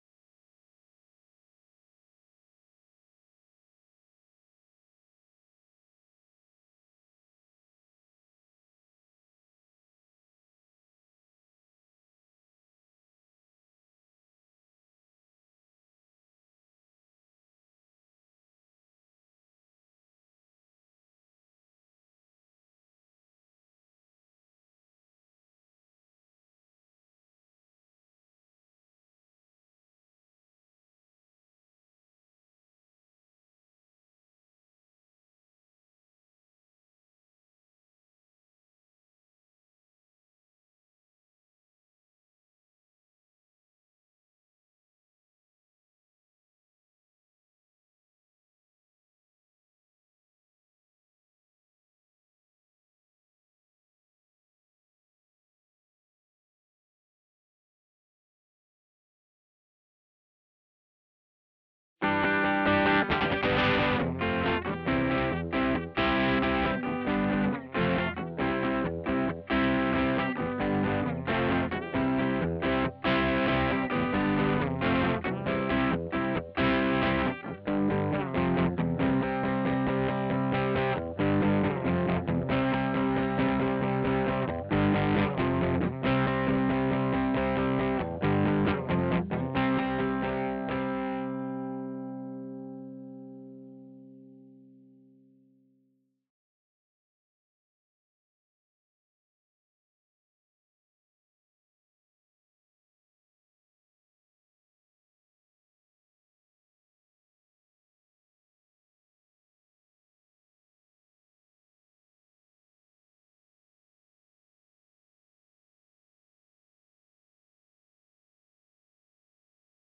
Crunch Guitar 1 Raw.wav